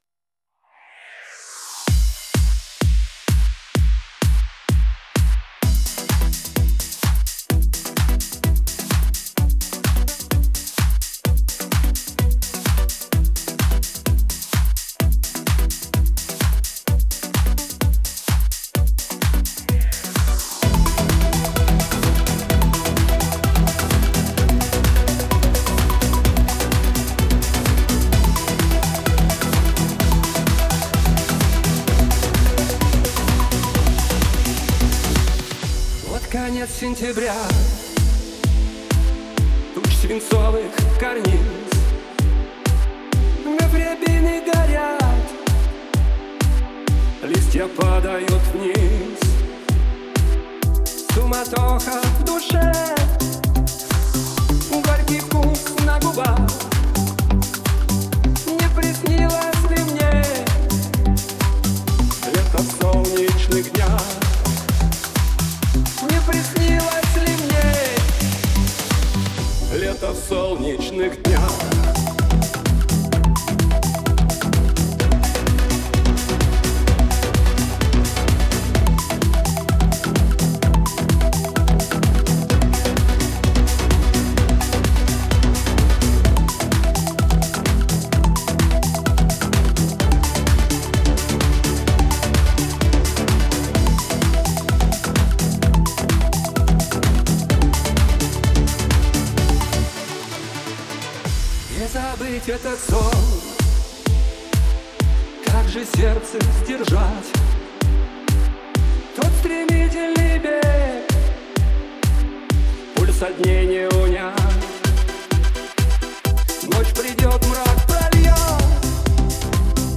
Вокс пришел одной дорогой (уже с бэками, fx-ми и прочим.